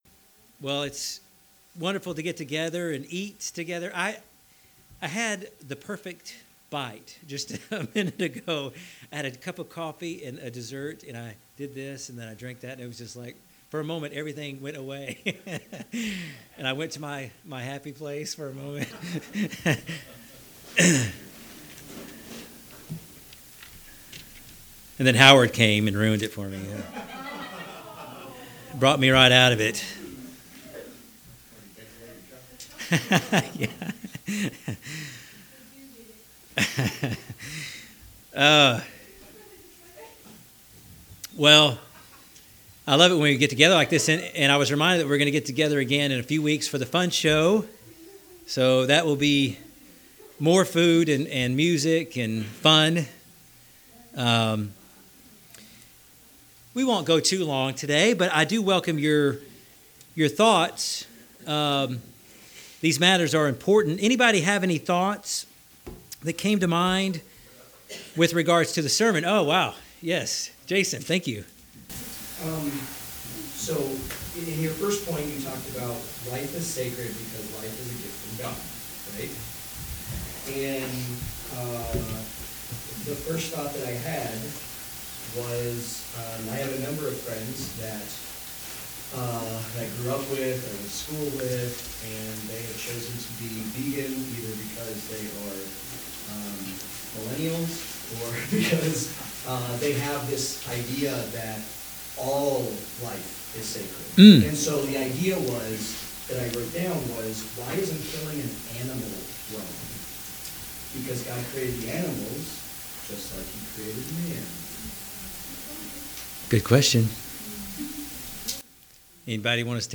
Bible Study: About participating in war, conscription, self-defense and covering other matters related to military service and violence.